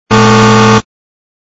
shorter_loop_klaxon.wav